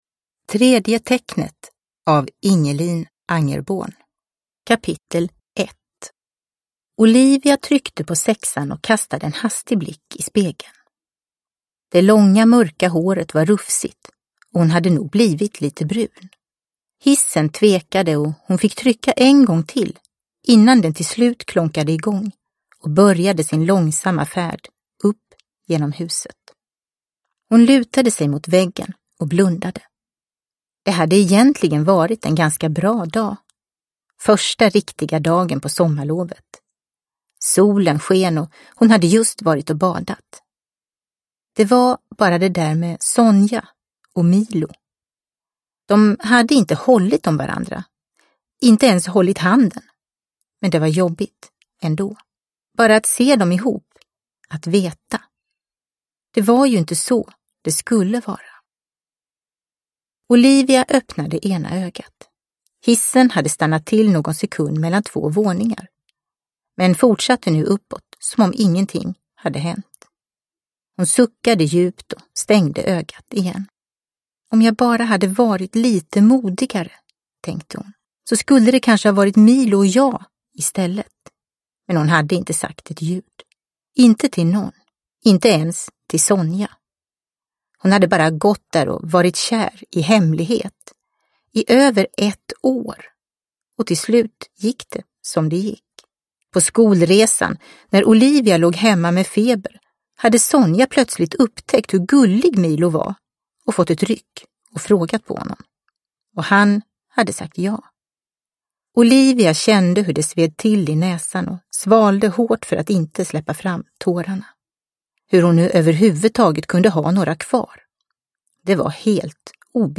Tredje tecknet – Ljudbok – Laddas ner